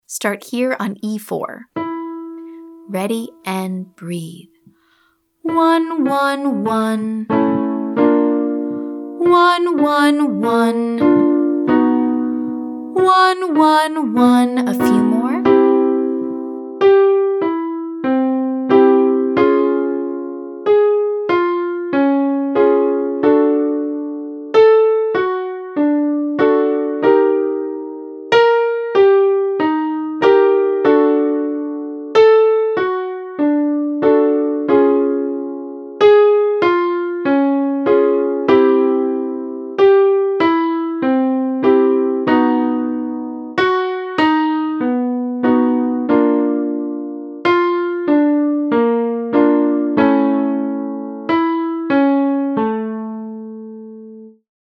In Part 2, we’ll focus on achieving a chest-dominant mix to retain the strength of your chest voice while accessing higher pitches. We’ll practice the word “ONE” with a spacious inhale to avoid shouting.
Essential Belting Warmup for High Voice 3A